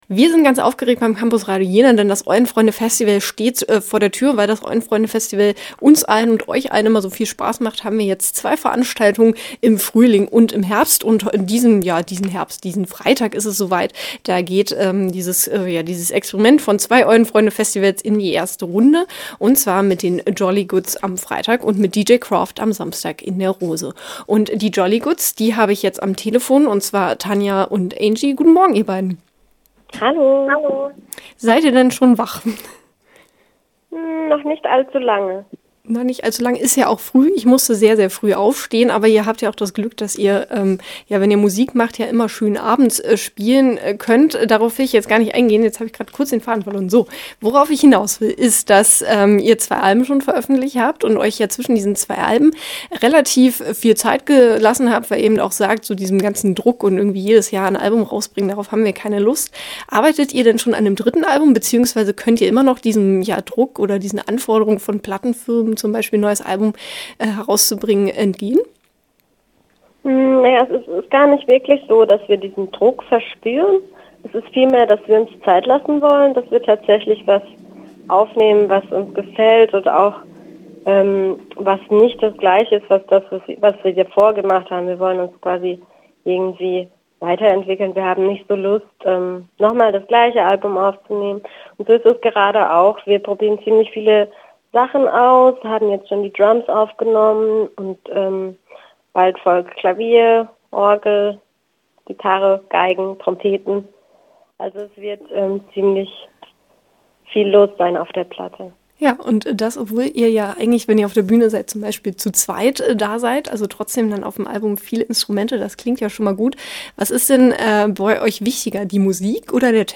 Im gespräch: Die Jolly Goods – Campusradio Jena